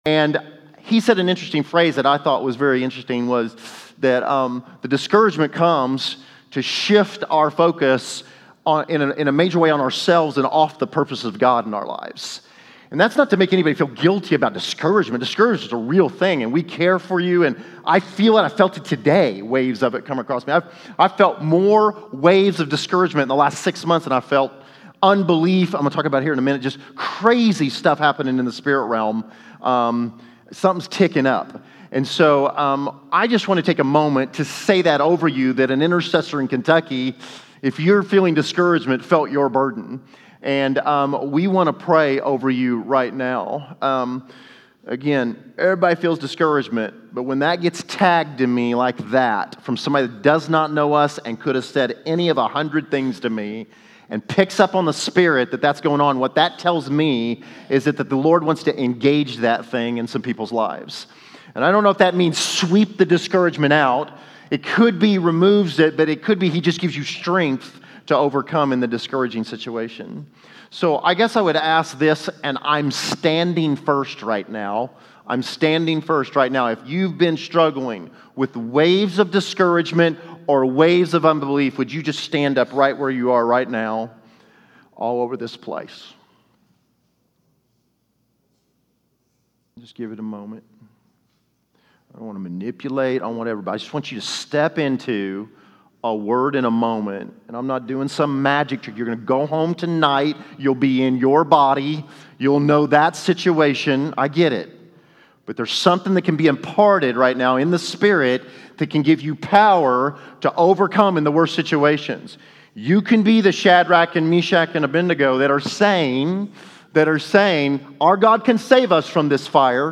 shares a short exhortation on dealing with discouragement.